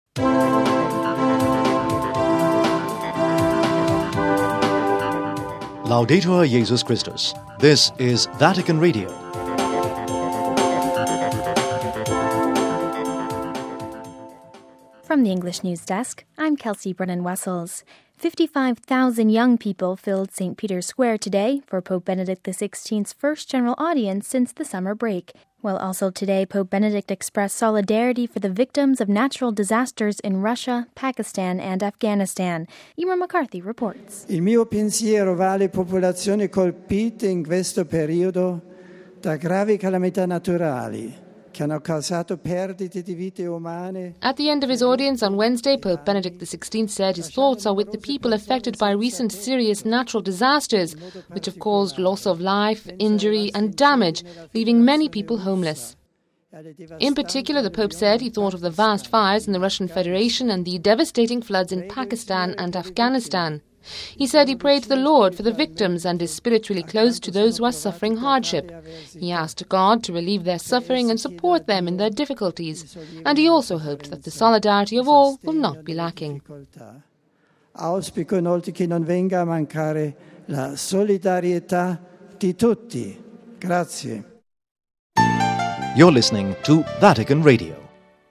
(04 Aug 10 – RV) At the end of the Wednesday general audience today Pope Benedict recalled the people affected by recent natural disasters such as fires and floods in Russia, Pakistan and Afghanistan, asking God to "relieve their suffering and support them in their difficulties, and express his hope that "solidarity will not be lacking."